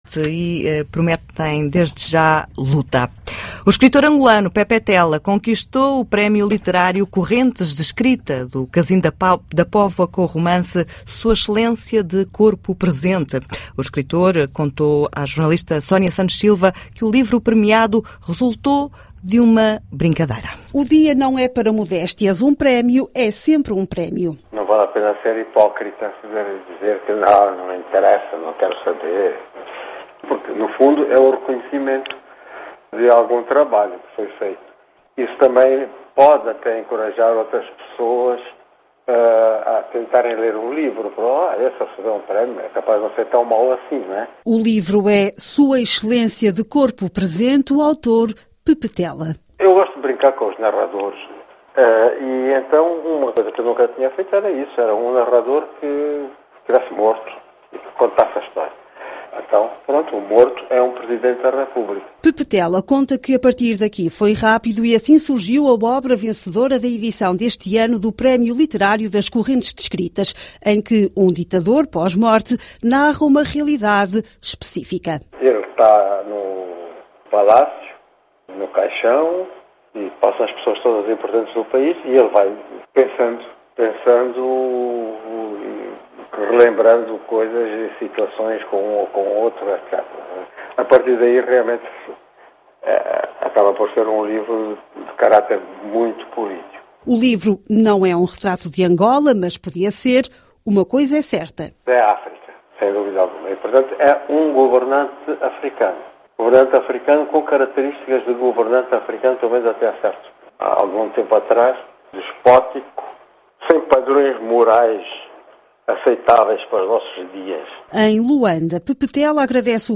O escritor angolano Pepetela conquistou o Prémio Literário Correntes d’ Escritas do Casino da Póvoa, com o romance “Sua Excelência, de Corpo Presente”. Declarações de Pepetela.